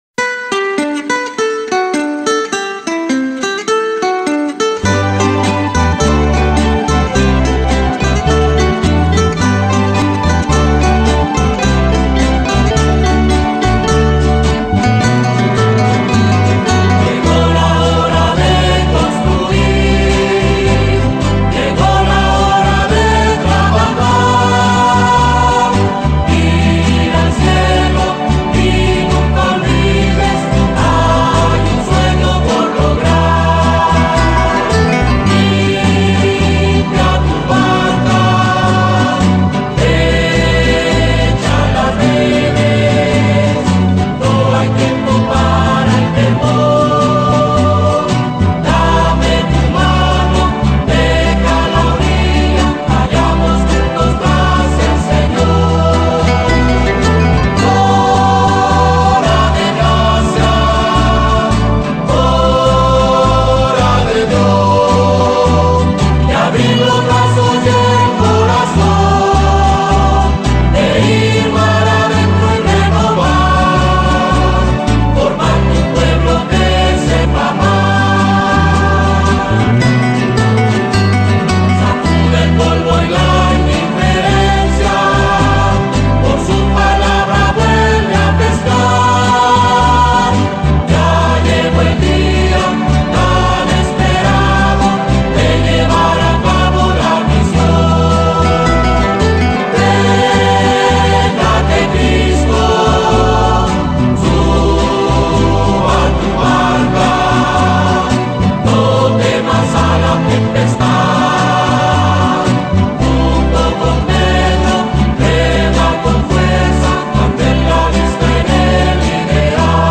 HIMNO-PLAN-PASTORAL-DIOCESIS-DE-SALTILLO.mp3